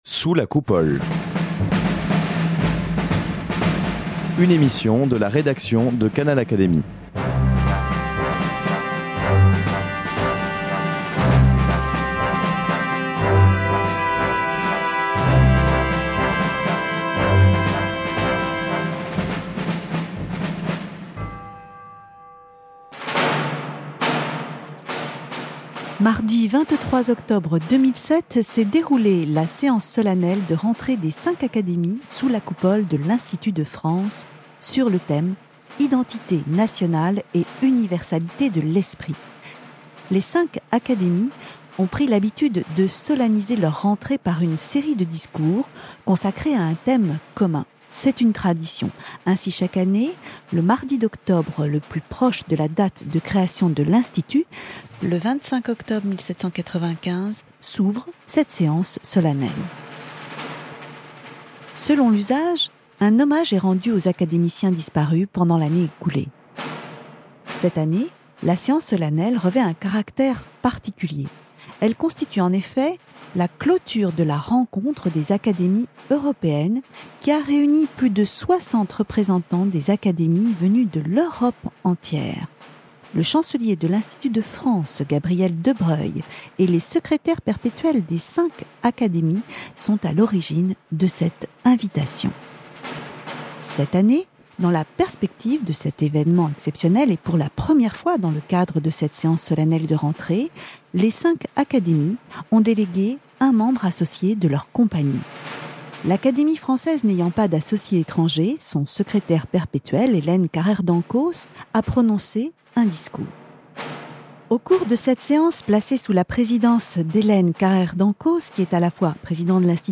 Séance solennelle de rentrée des cinq académies du 23 octobre 2007, académies européennes, Identités nationales et universalité de l’esprit- Institut de France,
A cette occasion, chaque académie délègue un confrère pour prononcer un discours en réponse au thème commun, choisi cette année-là. Selon l'usage, le président de l'Institut en exercice rend hommage aux académiciens disparus lors de l'année écoulée.